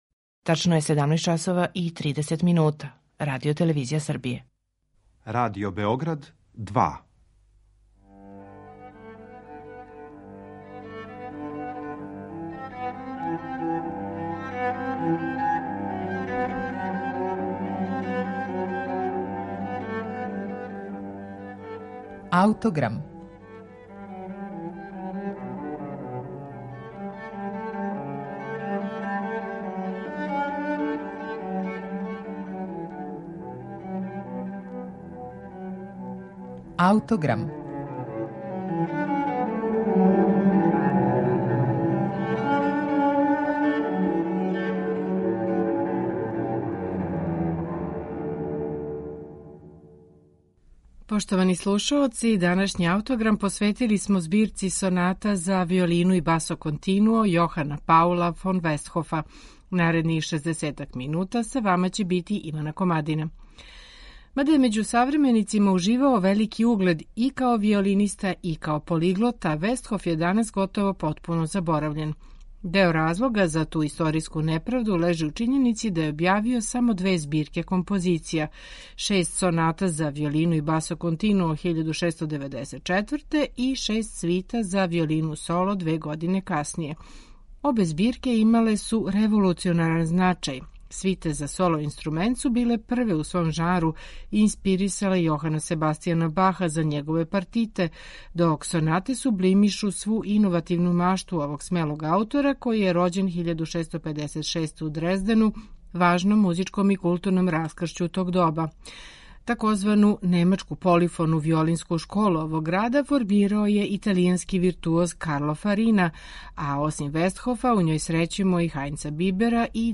сонате за виолину и basso continuo
на оригиналним инструментима епохе
виолина
виолончело
чембало
архилаута